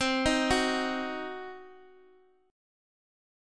Cdim Chord